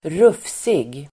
Ladda ner uttalet
Uttal: [²r'uf:sig]